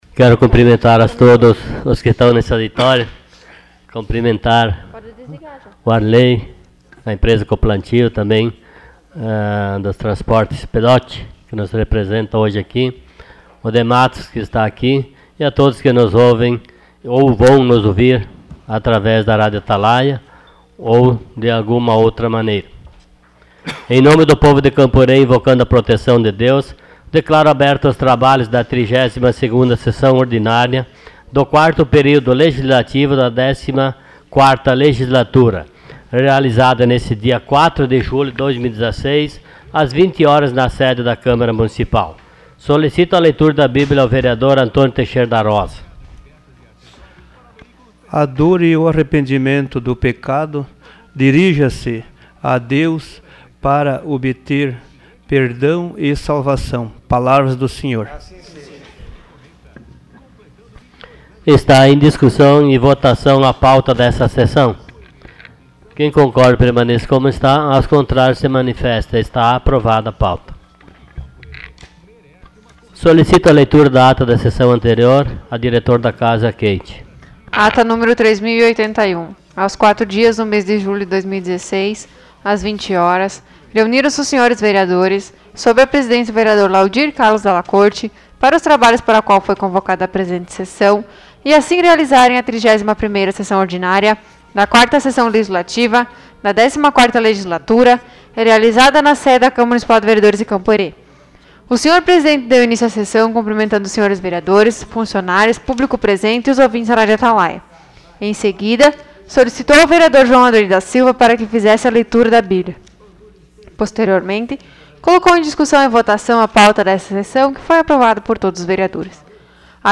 Sessão Ordinária dia 07 de julho de 2016.